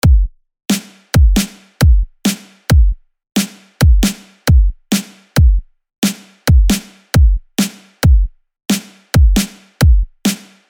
I was arranging the track with my DAW set to 3 beats per bar at 135bpm.
this drum pattern.